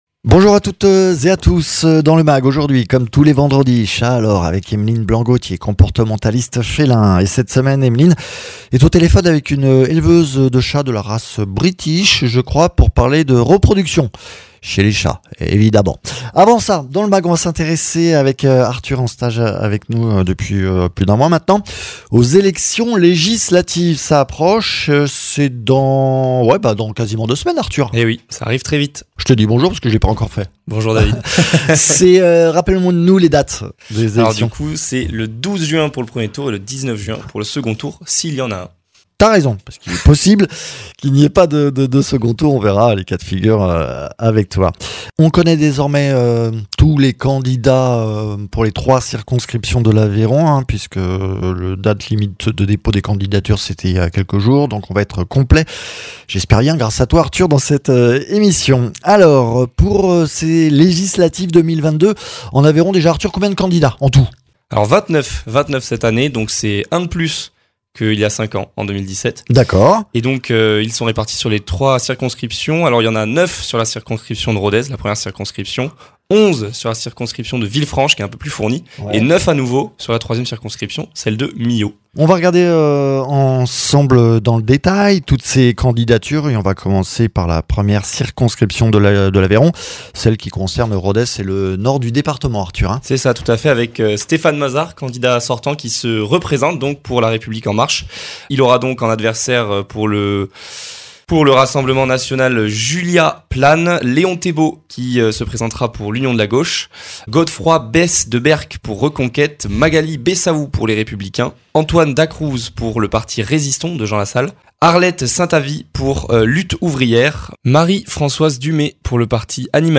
comportementaliste félin
éleveuse de chats.